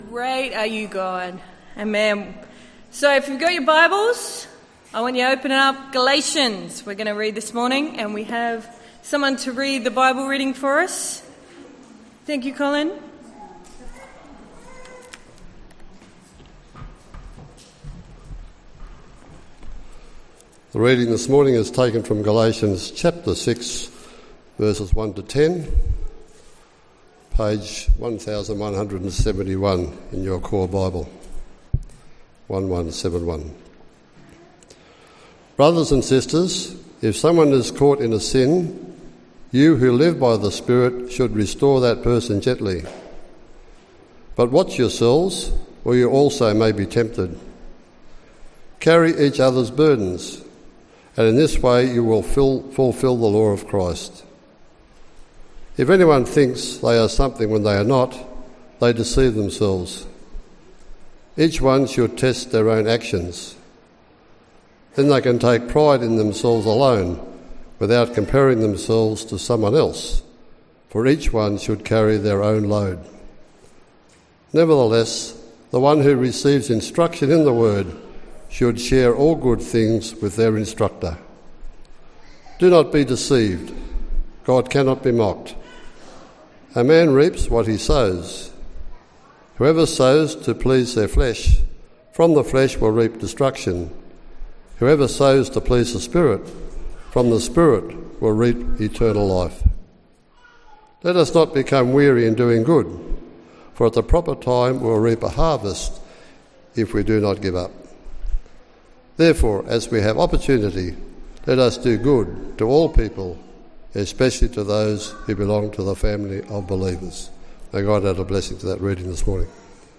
Bible reading and message from the 10AM meeting at Newcastle Worship & Community Centre of The Salvation Army. The Bible reading was taken from Galatians 6:1-10.